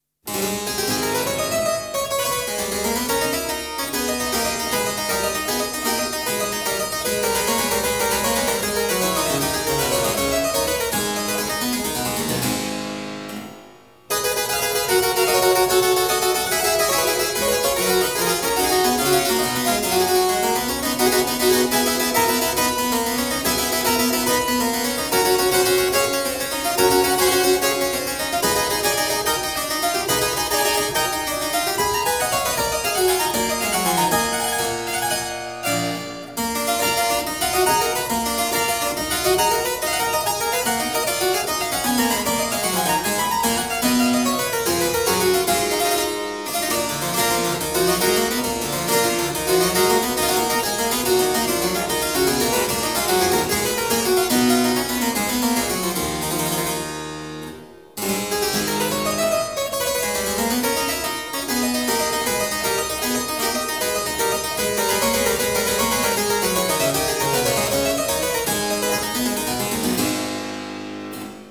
索勒 / 大鍵琴作品
而這也讓她在演奏這些作品時能深入到大鍵琴機能的內在，重現巴洛可的活潑精神。